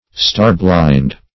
Star-blind \Star"-blind`\ (-bl[imac]nd`), a.